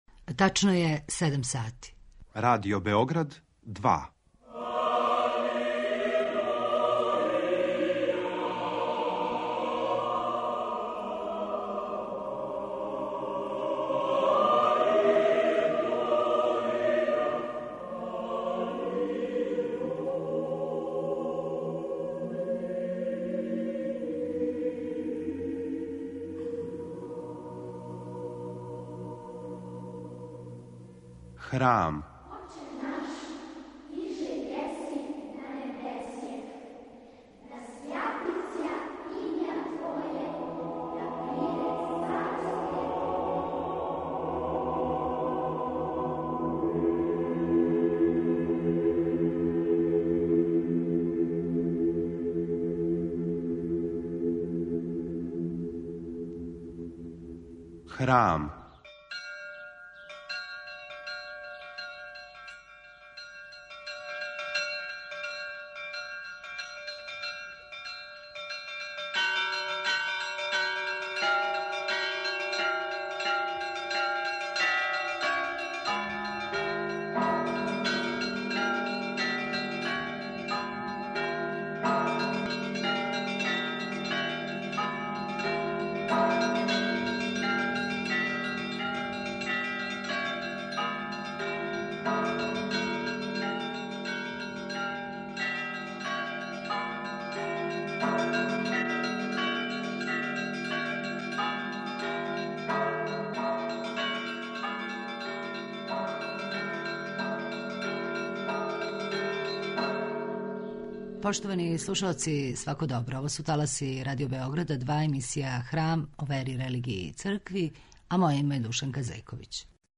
Говори: Митрополит црногорско-приморски Амфилохије (7. јануар 1938, Баре Радовића, Доња Морача - 30. октобар 2020, Подгорица)
Поводом упокојења у Господу митрополита црногорско-приморског Амфилохија (Радовића) у овонедељном Храму слушаћете једно од предавања високопреосвећеног митрополита о српској цркви и њеној мисији у савременом свету.